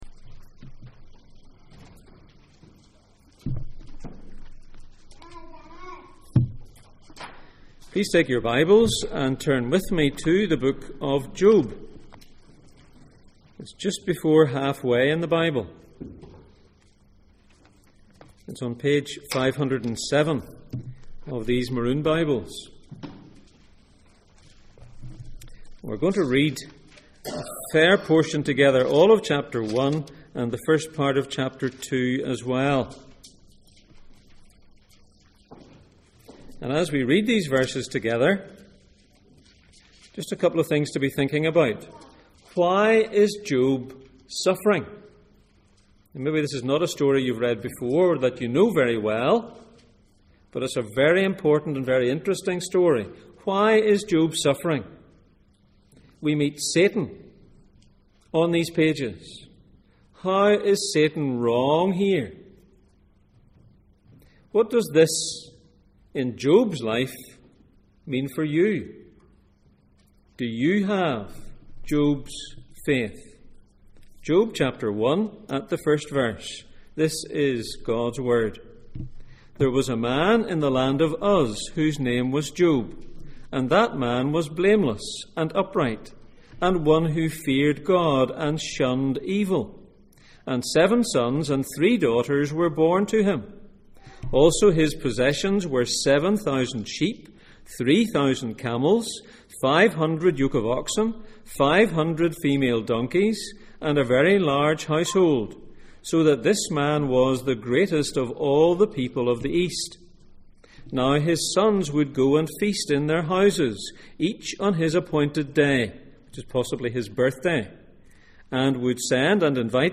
Questions from Job Passage: Job 1:1-2:10, Job 19:25-26, Mark 8:34-38 Service Type: Sunday Morning %todo_render% « The life of the Elder How can I be right with God?